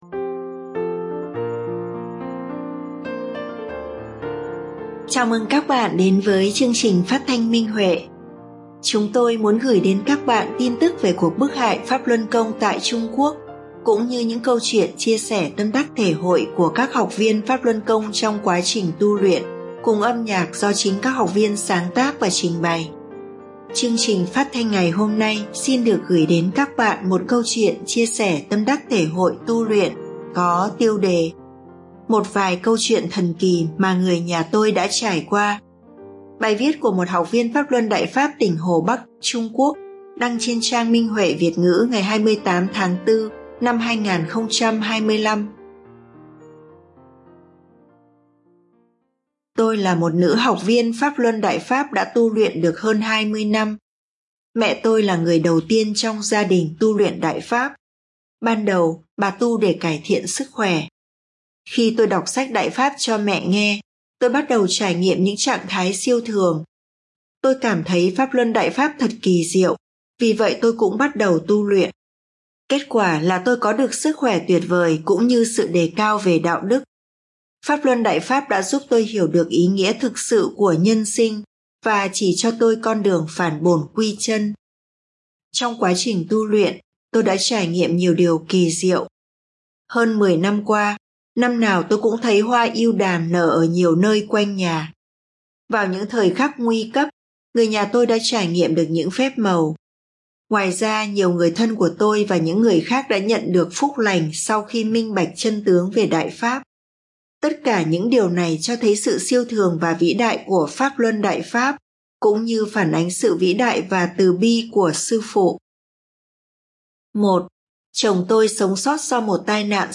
Phát thanh Minh Huệ (Câu chuyện tu luyện): Một vài câu chuyện thần kỳ mà người nhà tôi đã trải qua